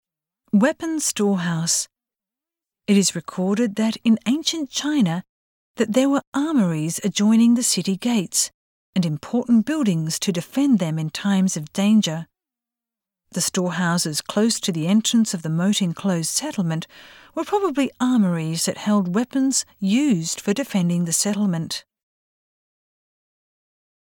The storehouses close to the entrance of the moat-enclosed settlement were probably armories that held weapons used for defending the settlement. Voice guide PREV NEXT Keitai-Guide TOP (C)YOSHINOGARIHISTORICAL PARK